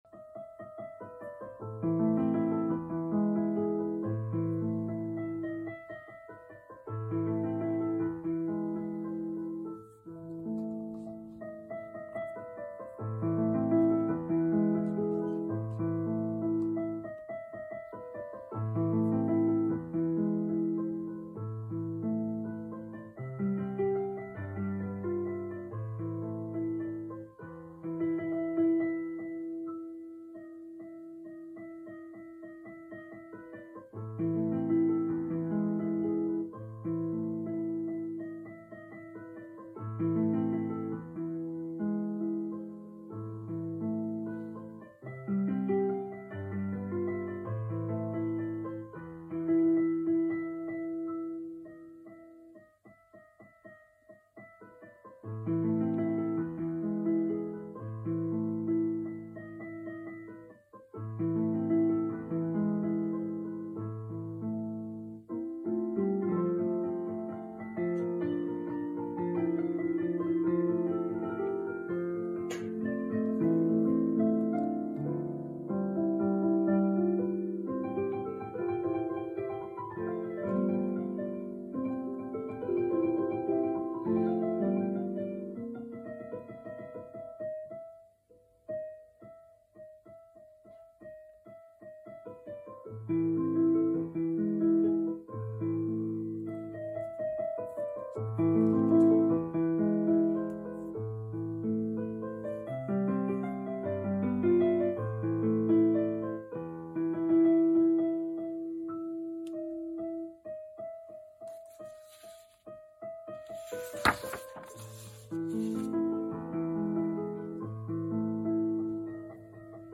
Zongoragyakorlás és a revízió utolsó órái között...
Beethoven: Für Elise (teljes) 2022. április 22.-i éjjeli gyakorlás, felelevenítés felvétele>>  (ld. video) Több mint ötven év után, sajnos egy-két pontatlansággal)...